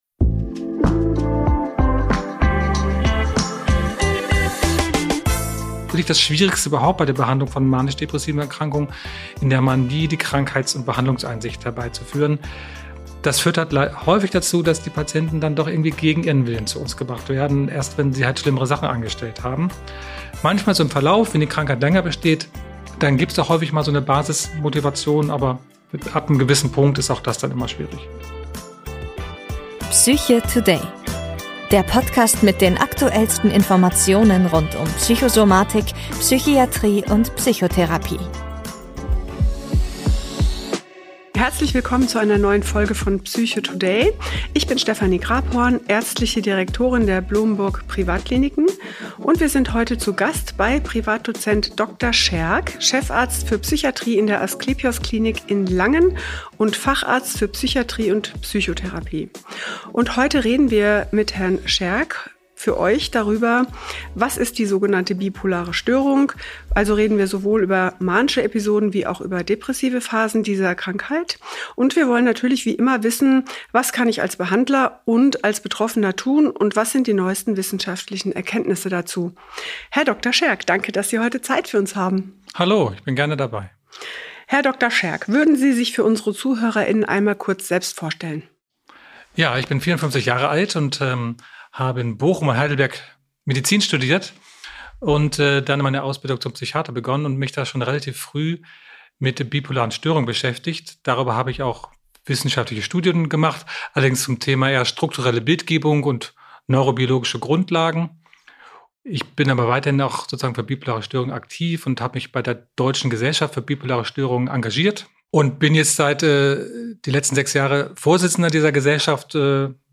Ein spannendes Gespräch über wissenschaftliche Erkenntnisse, praxisnahe Versorgungskonzepte und die Bedeutung moderner Psychiatrie.